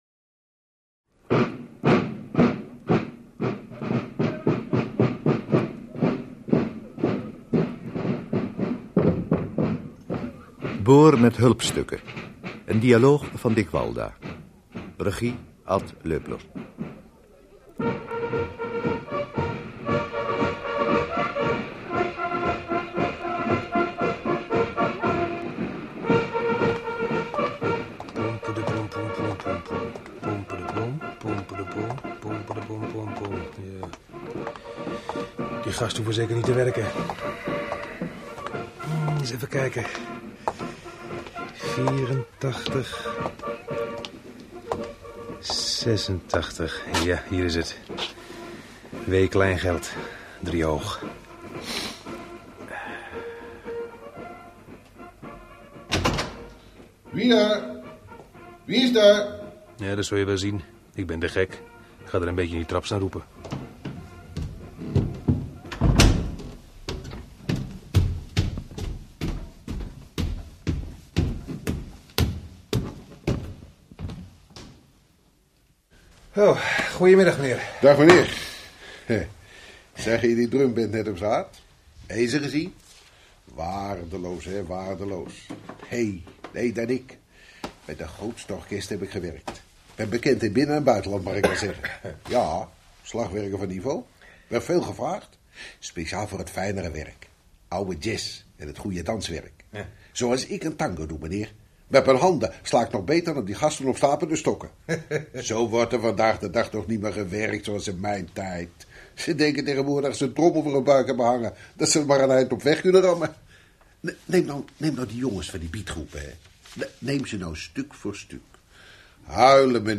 Dit 1-delige hoorspel duurt ruim 10 minuten.
Verhaal: Een dialoog tussen een verkoper van een boormachine met hulpstukken en een eenzame man in rolstoel. De koop loopt niet als verwacht…